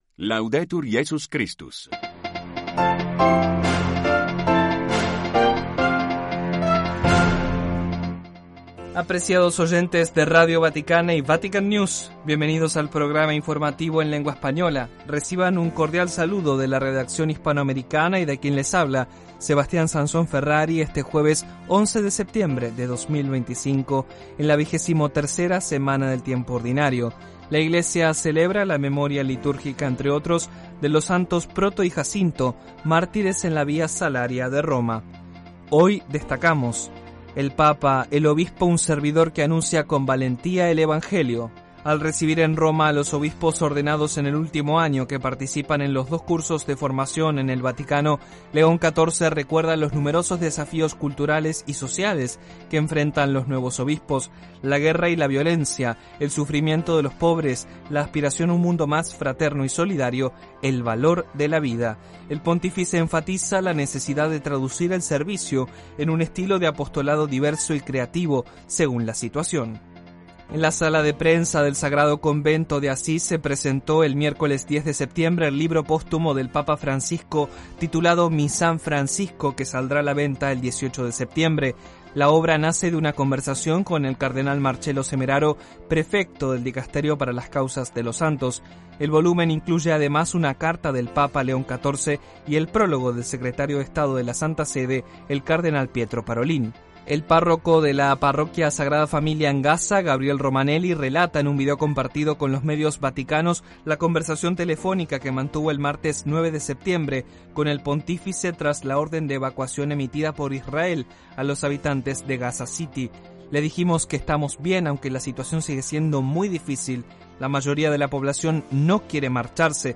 Informativos diarios en español